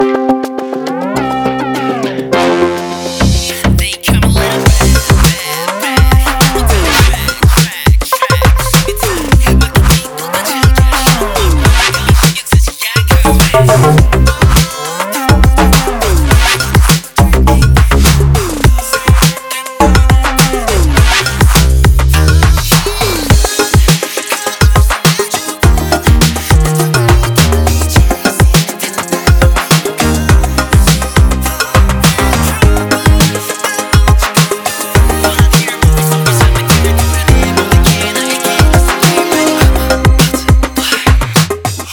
Для примера, захотелось мне k-pop.